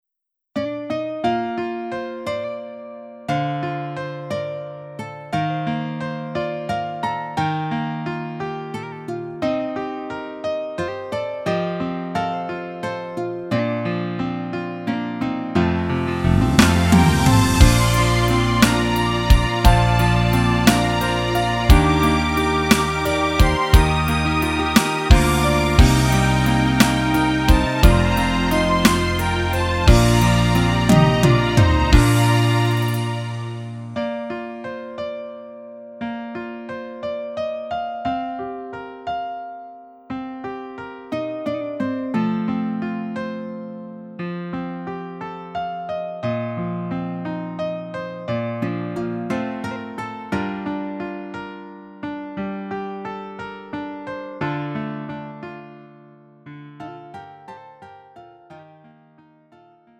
음정 원키 4:48
장르 가요 구분 Lite MR